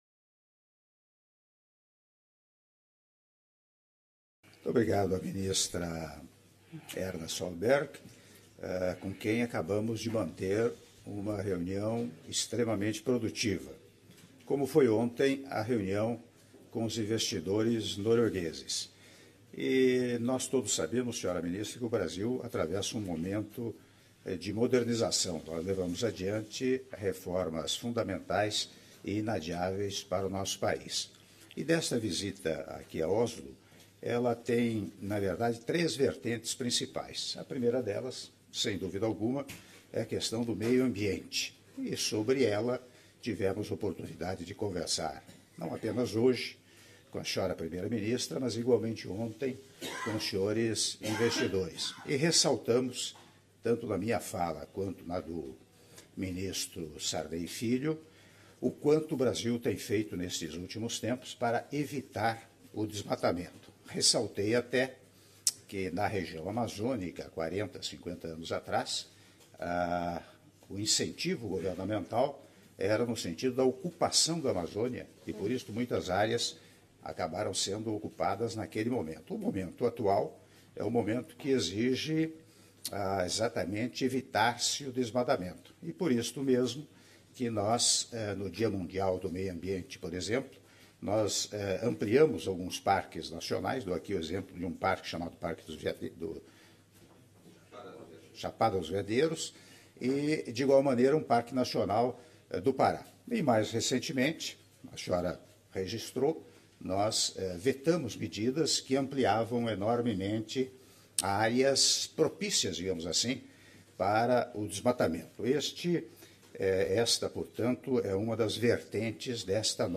Áudio do discurso do Presidente da República, Michel Temer, na conferência de imprensa após encontro com a primeira-ministra da Noruega, Erna Solberg - (07min18s) - Oslo/Noruega